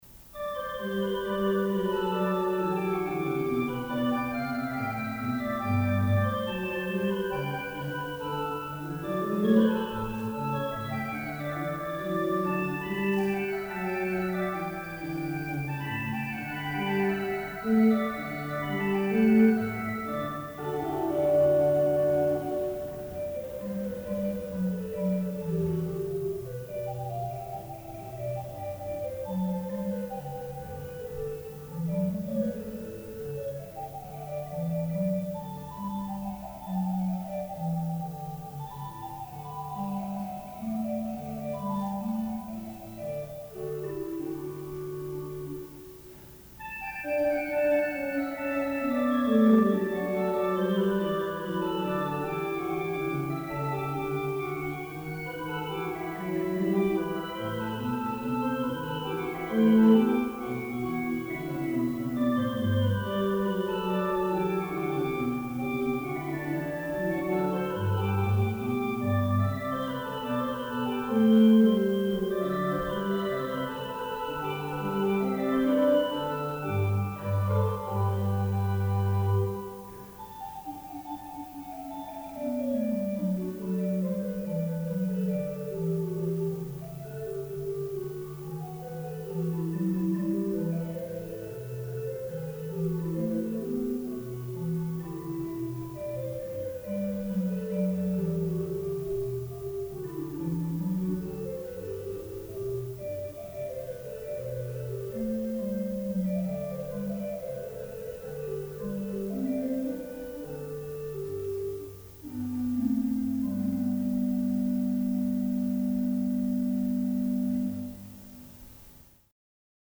Het Orgel
Het orgel sprak toen dus helemaal vrij zonder belemmering van banken.
In dit stuk hoort u de Quint 3 van het hoofdwerk als uitkomende stem.